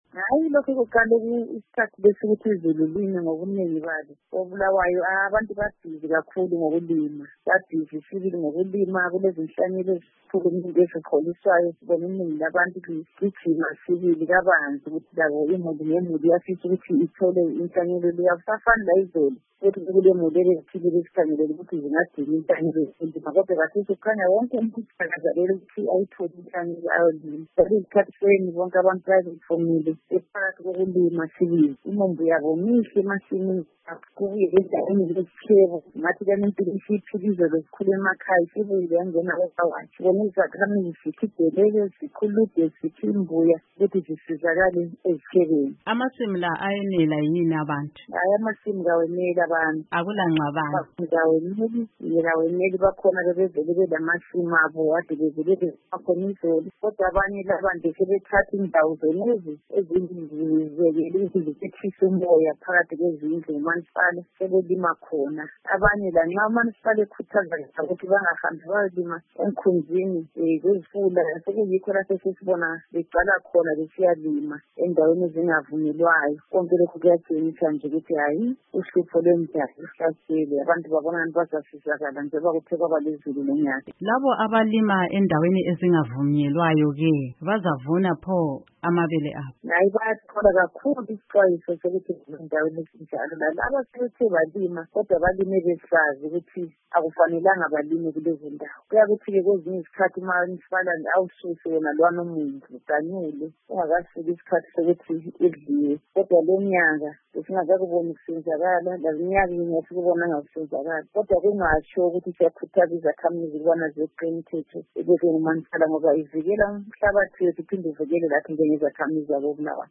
Ukuze sizwe okwenzakala koBulawayo ngaloludaba, sixoxe loKhansila Ntombizodwa Khumalo kaWard 23 eNkulumane.
Ingxoxo loKhansila Ntombizodwa Khumalo